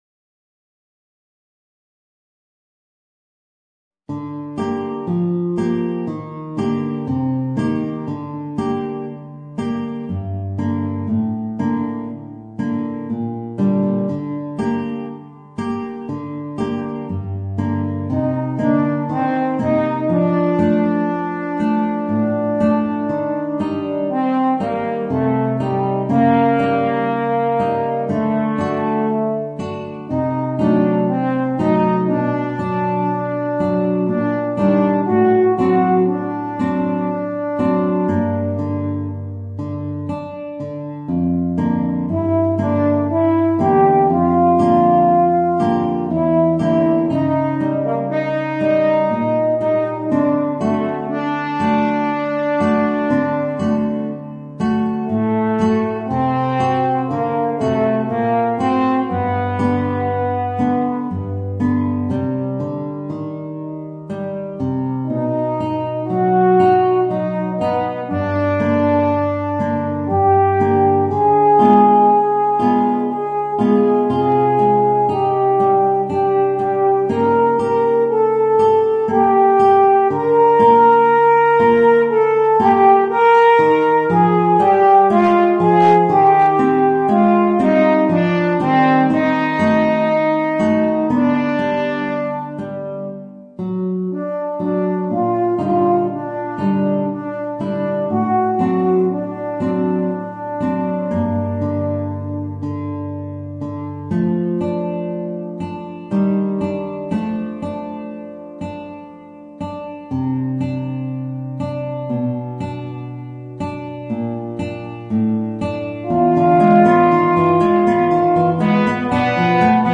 Voicing: Horn and Guitar